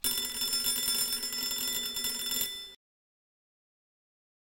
altes-telefon.mp3